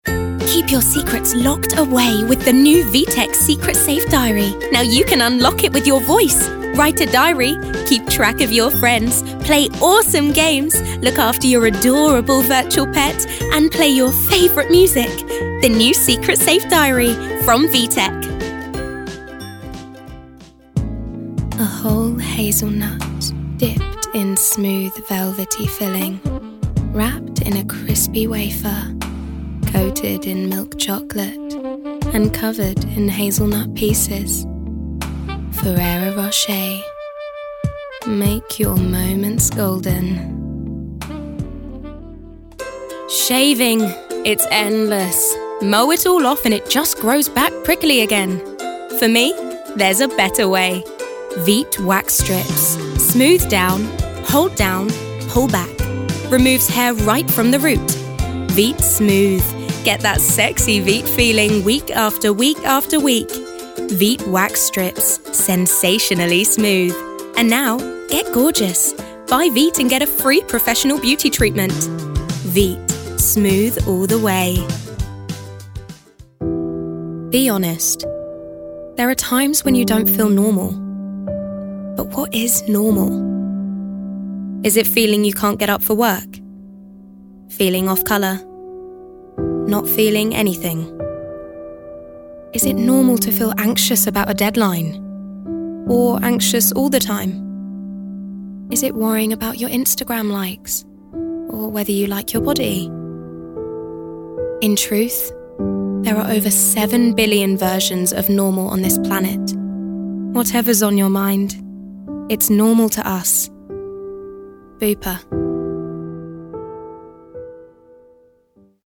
Commercial Showreel
a warm, playful and clear-toned voice, with an abundance of accents and characters to play with!
Her youthful, relatable tone is also great for those conversational commercials!
Female
Neutral British